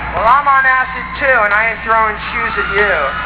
Perry's Voice: